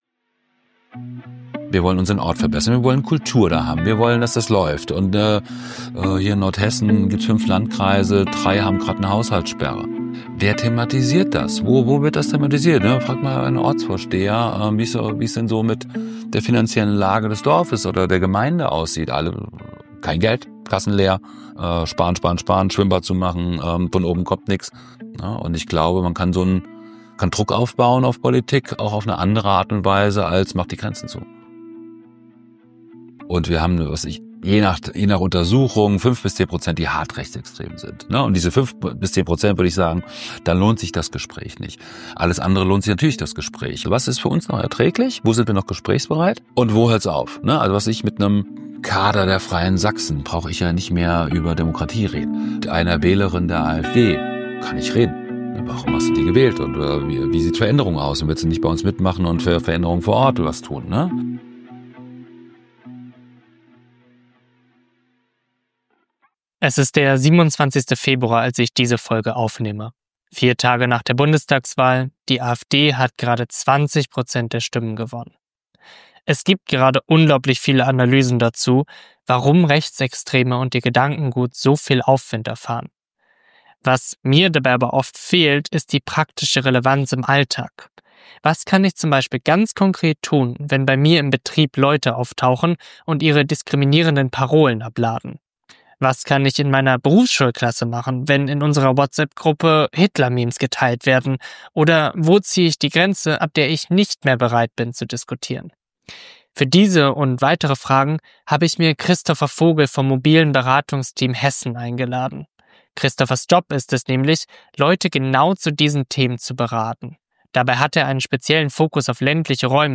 [Interview]